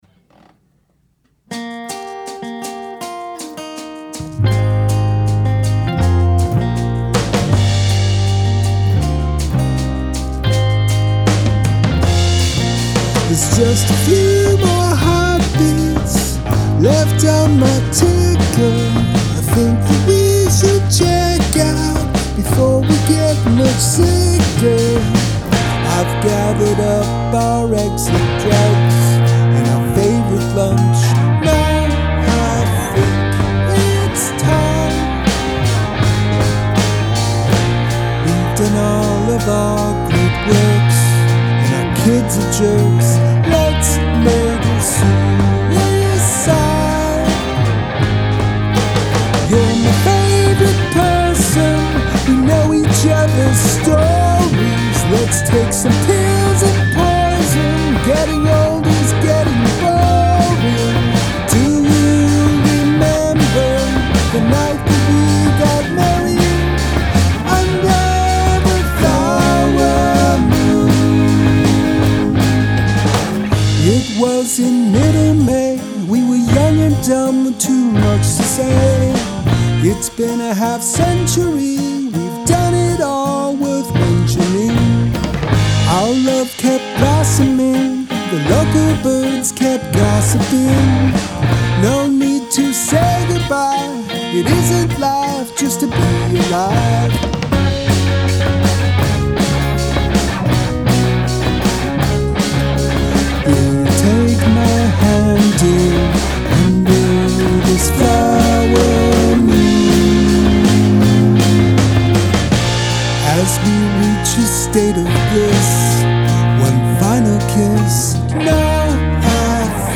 Narrative song about a murder